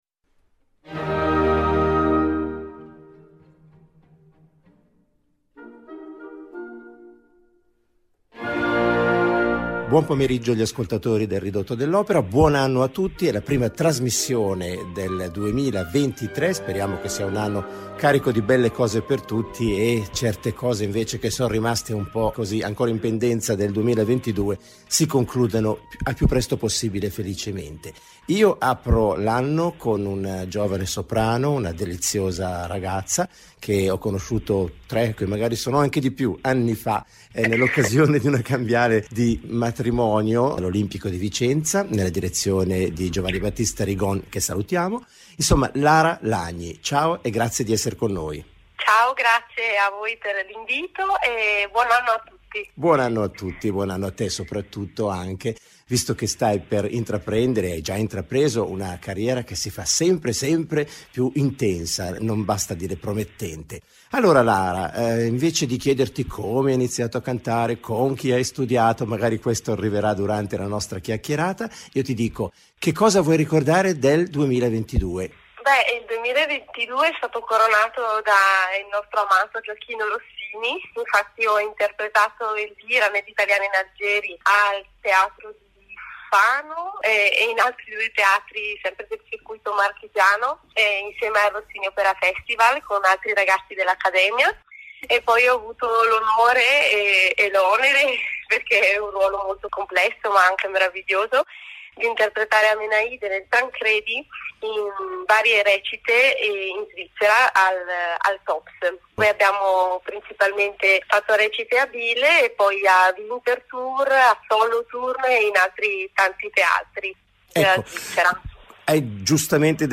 Ridotto dell'opera